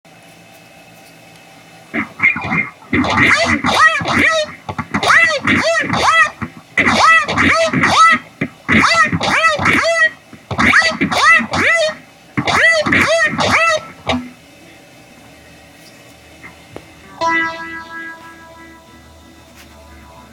地震速報のときのあれ。
ギターでエミュレートしてみた。
アームダウンした状態で、張力が一番低い３弦をアープ戻しながらピッキングするだけ。